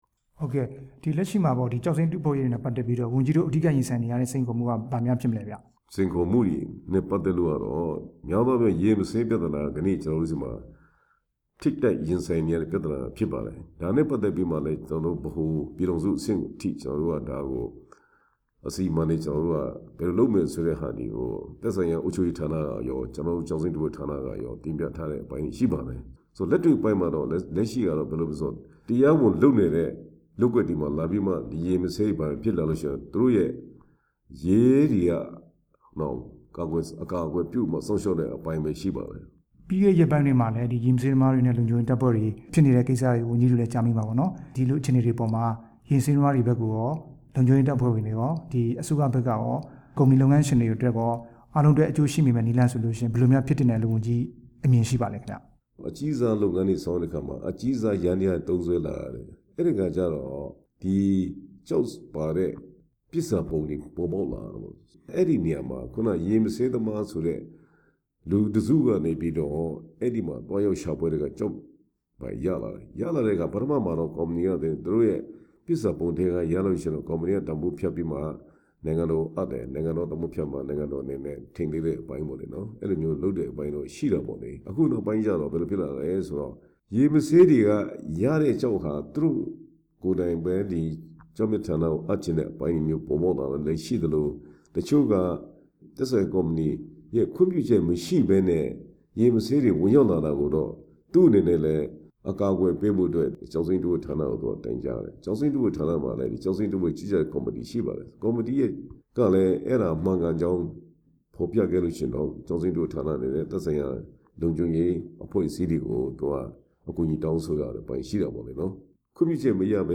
ရေမဆေးသမားများ ပြဿနာ အကြောင်း မေးမြန်းချက်